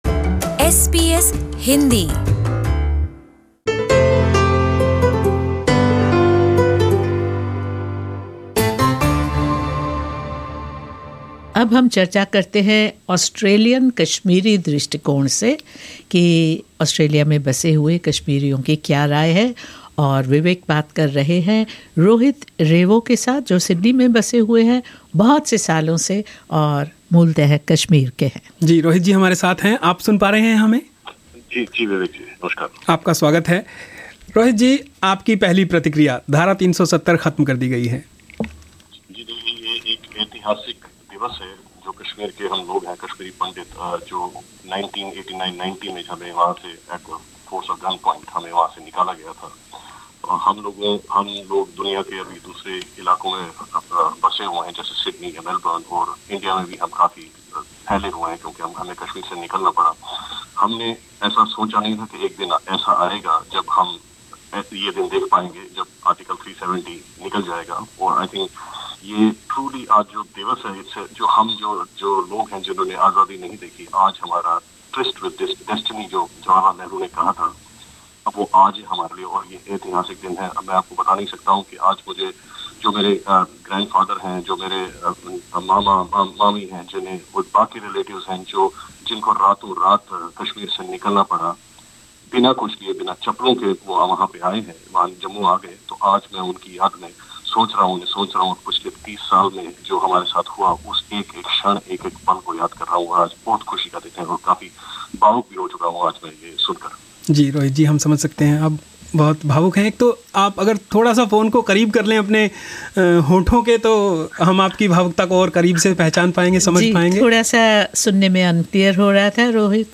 सुनिए यह पूरी बातचीत...